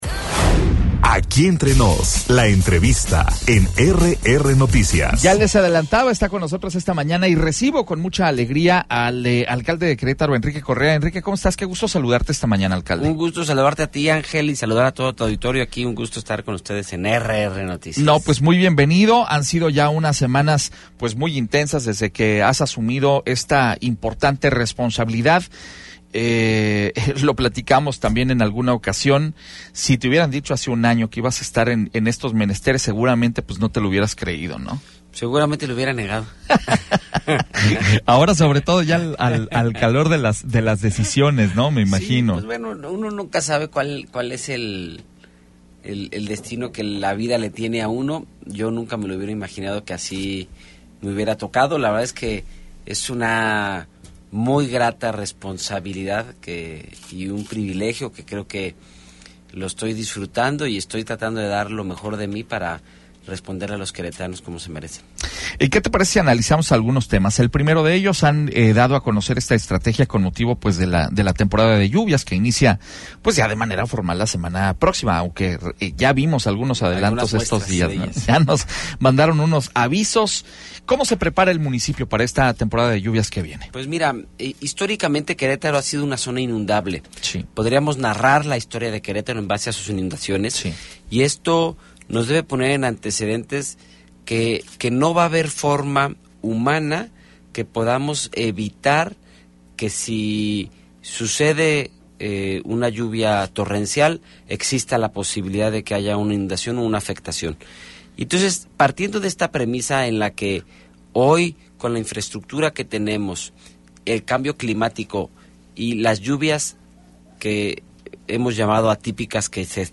Entrevista con el presidente municipal de Querétaro, Enrique Correa Sada - RR Noticias
Entrevistas